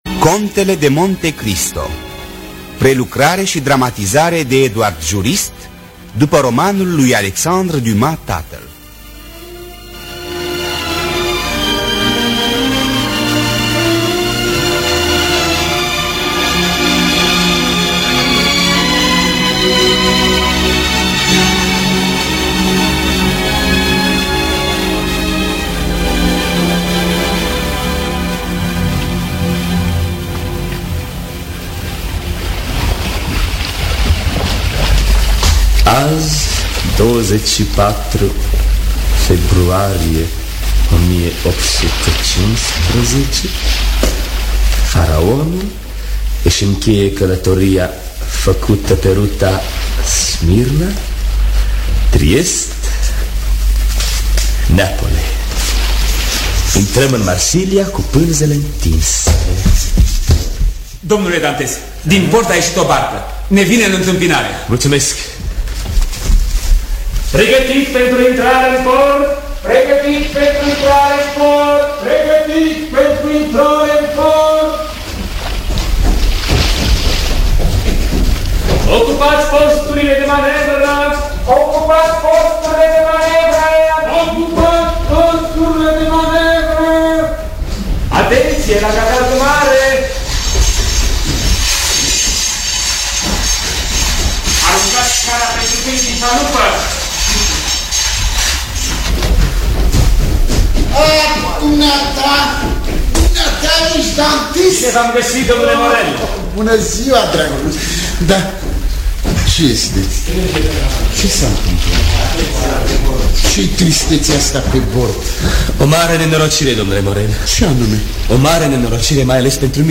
Adaptarea și dramatizarea radiofonică